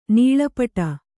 ♪ nīḷa paṭa